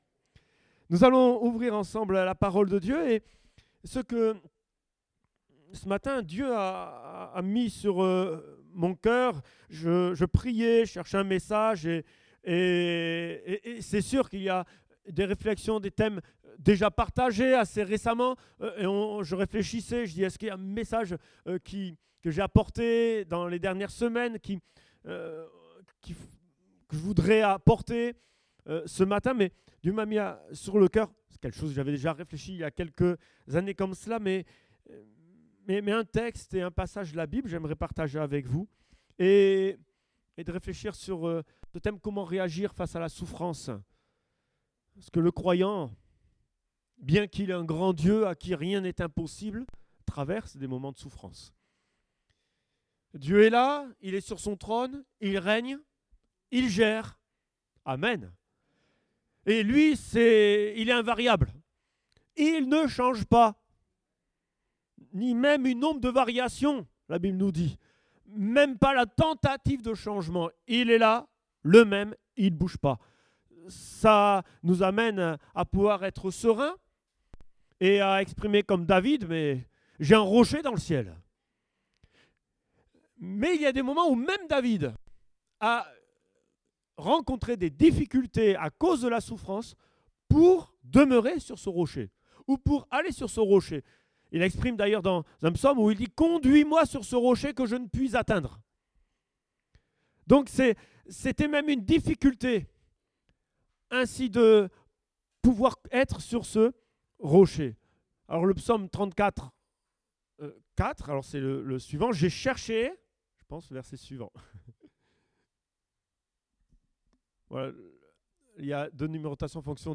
Date : 3 juin 2018 (Culte Dominical)